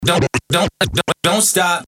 S – DON’T STOP – SCRATCHED
S-DONT-STOP-SCRATCHED.mp3